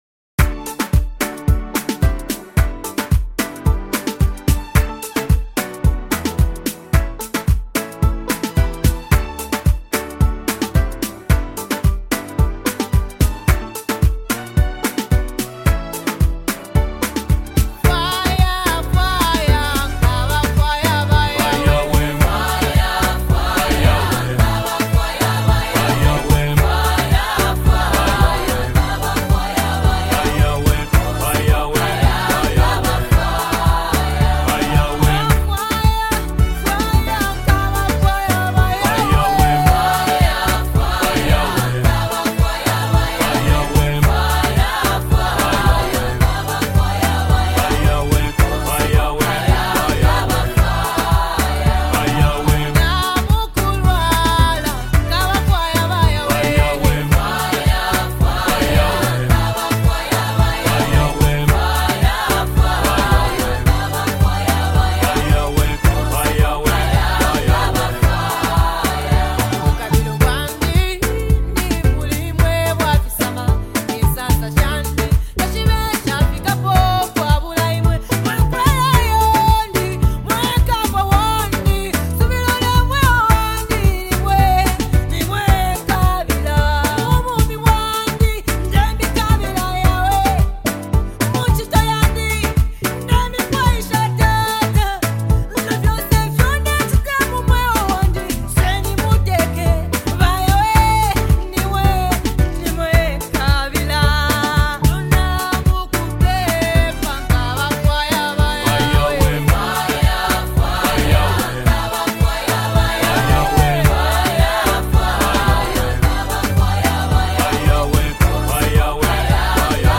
Gospel Music
With its soul-stirring rhythm and anthemic chorus